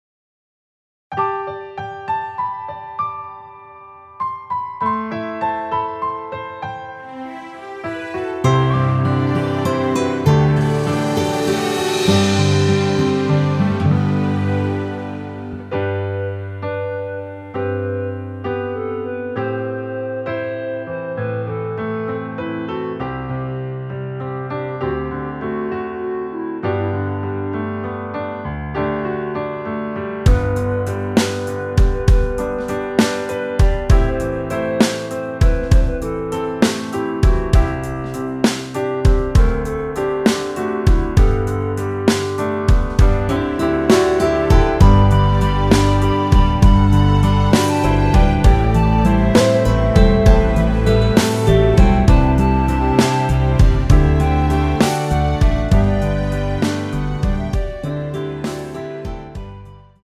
원키에서(-1)내린 멜로디 포함된 짧은 편곡 MR입니다.
앞부분30초, 뒷부분30초씩 편집해서 올려 드리고 있습니다.
중간에 음이 끈어지고 다시 나오는 이유는